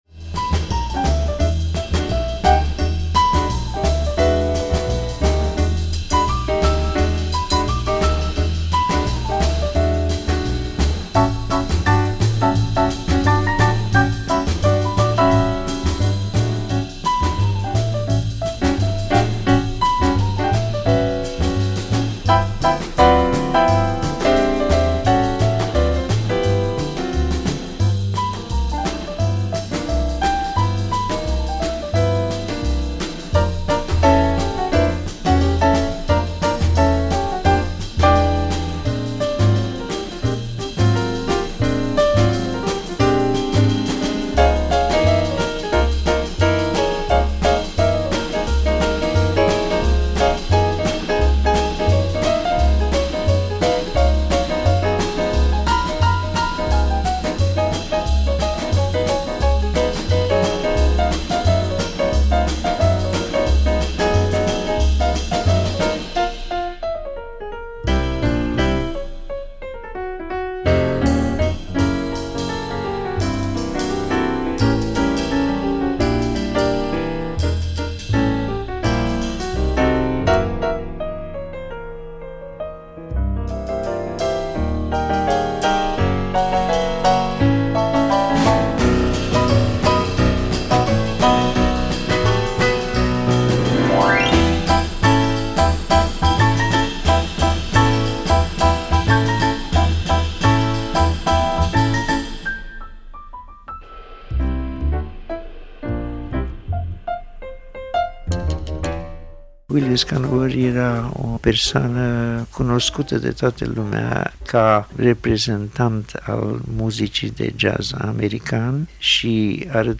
Il vom asculta si pe admiratorul sau de o viata, pianistul si compozitorul Marius Popp, explicand ce rol a avut Jancy Körössy in jazzul din Romania si cat de importanta este in prezent muzica sa si contributia sa artistica.
piano
Restituiri esentiale pentru colectia noastra de jazz – Jancy Körössy  – interviu si muzica – povestea vietii sale: Körössy – plecat din Cluj, la Bucuresti – apoi pe ruta Franta – Germania – Statele Unite, si inapoi in Romania pana in ianuarie 2013…